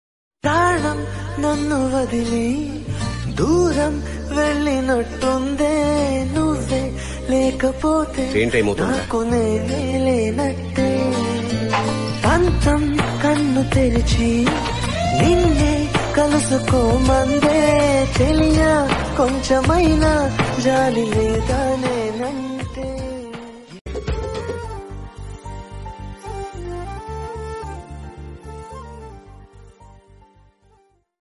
best flute ringtone download | love song ringtone
melody ringtone download
emotional ringtone